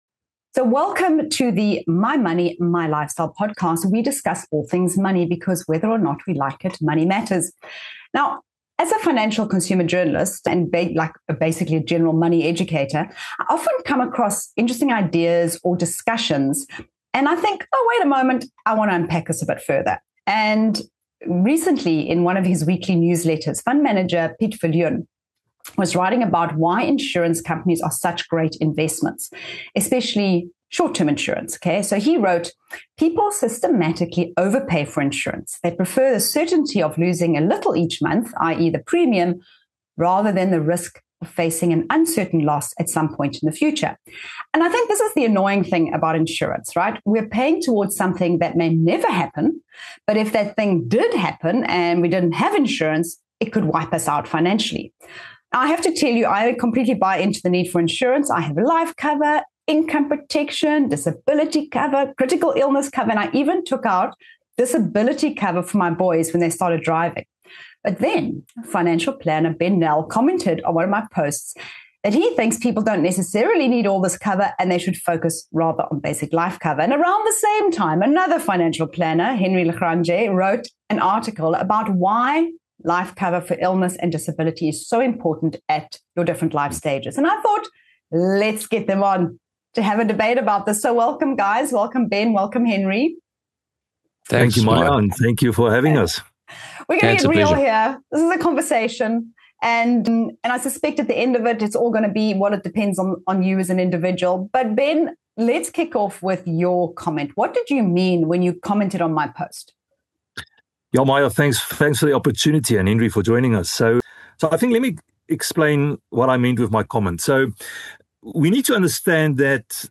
debates the relevance of insurance and how much is enough with financial planners